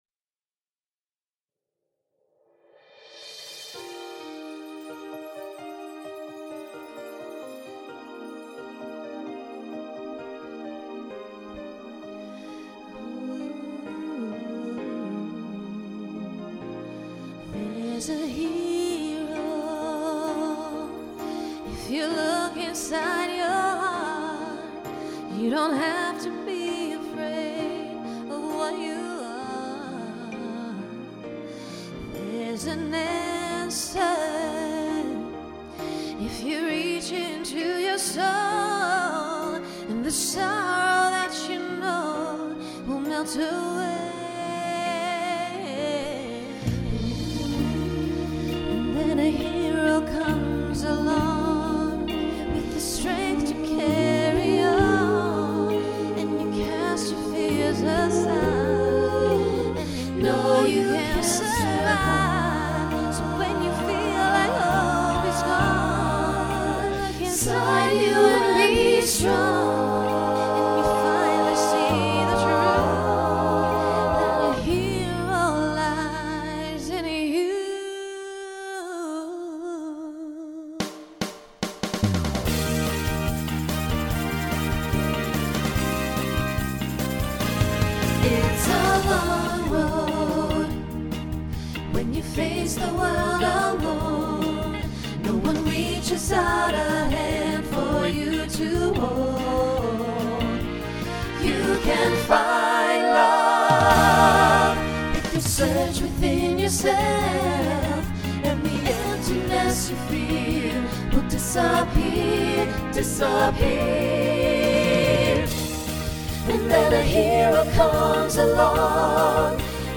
Voicing SATB Instrumental combo Genre Pop/Dance